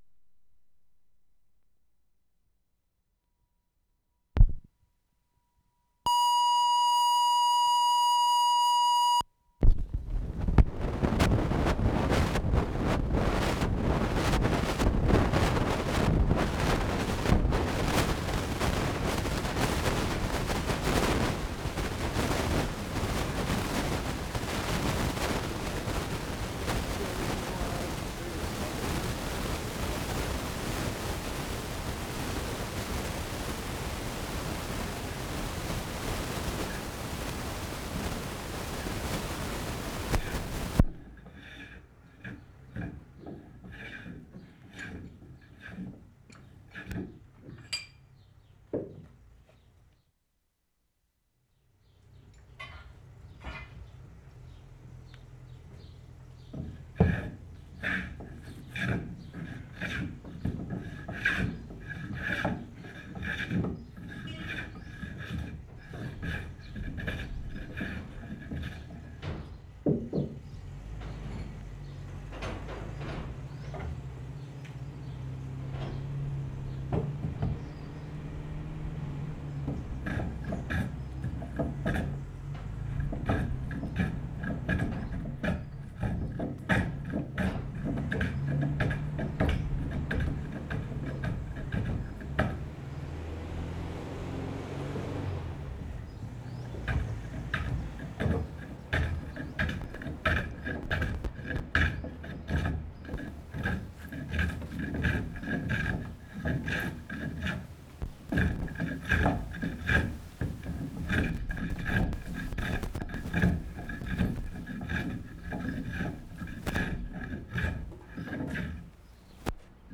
Lesconil, France April 16/75
BOAT BUILDING, PLANING WOOD (4 takes)
a) useless; bad disturbance in mic.
b) very short take, planing wood.
c) longer, better take, including car horn, car idling in background. (toward end of take, disturbance in mic).
d) first planing wood, then truck passing, men clunking around in truck, then putting clamps on wood.